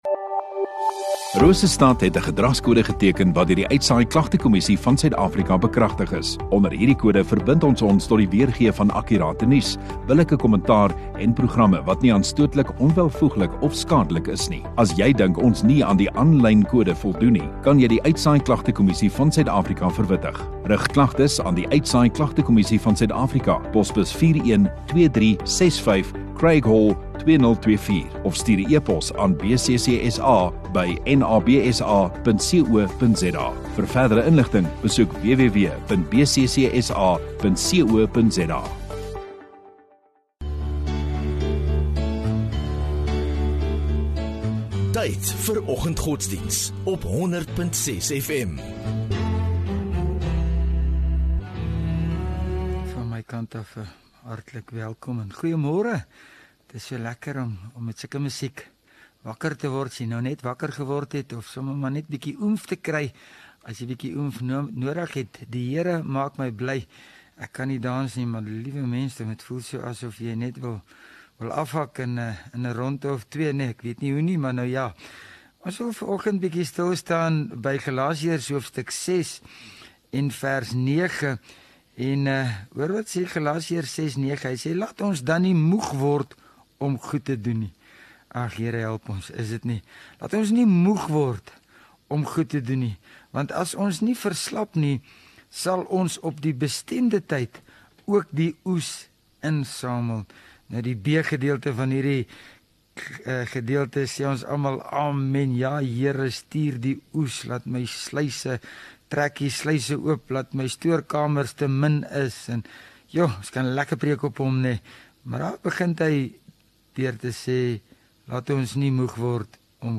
27 Aug Woensdag Oggenddiens